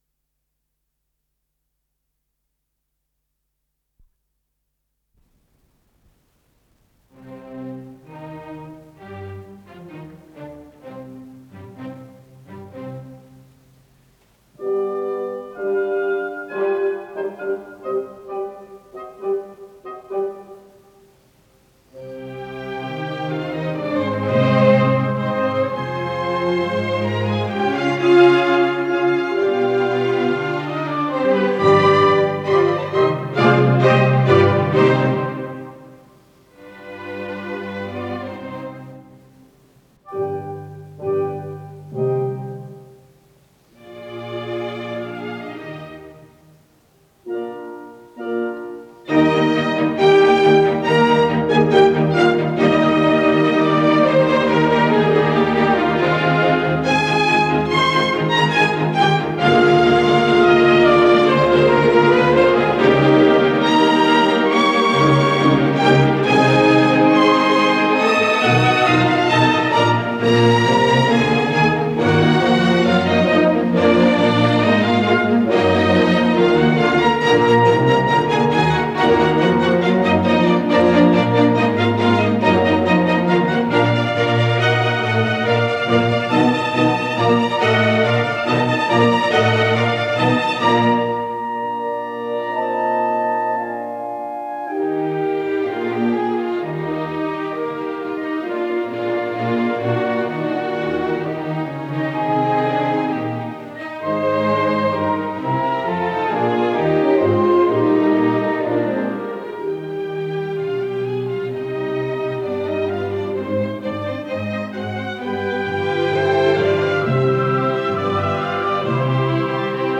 Исполнитель: Эмиль Гилельс - фортепиано
До минор